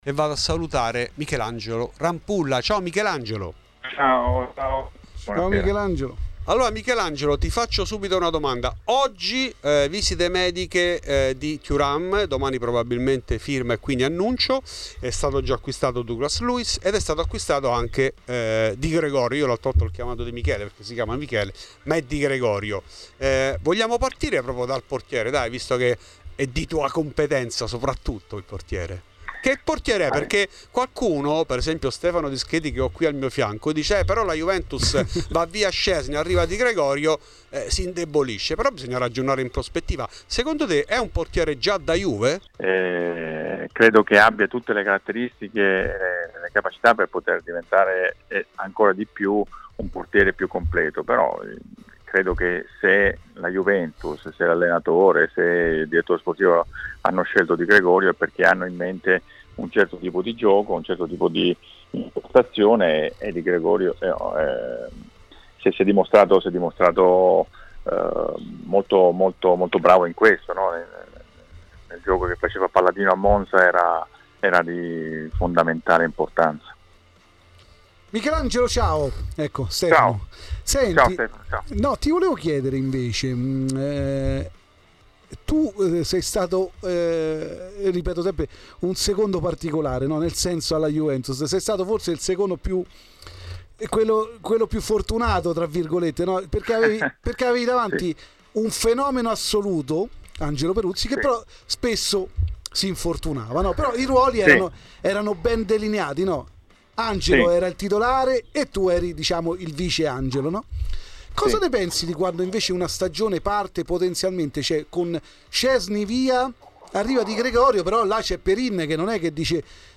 In ESCLUSIVA a Fuori di Juve Michelangelo Rampulla, dal 1992 al 2002 secondo portiere dei bianconeri, poi allenatore degli estremi difensori della Vecchia Signora.
Nel podcast l'intervento integrale